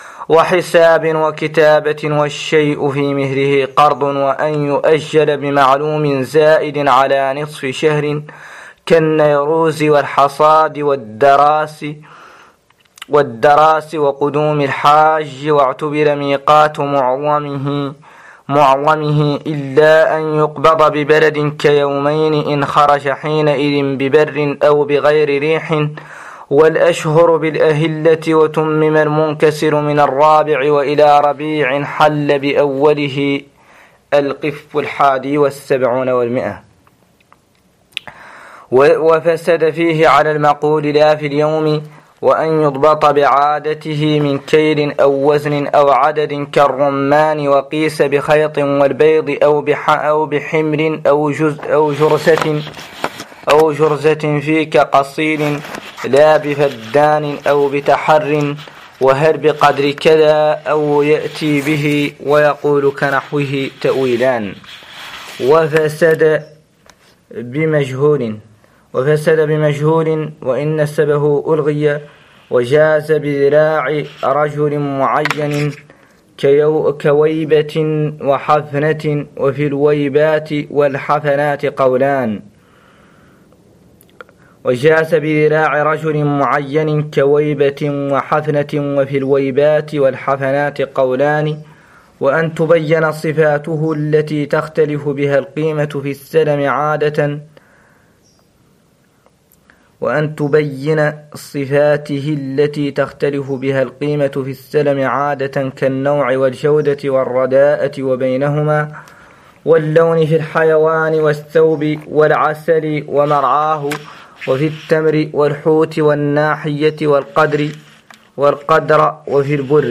قراءة لمتن مختصر خليل 08